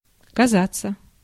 Ääntäminen
France: IPA: /sɑ̃.ble/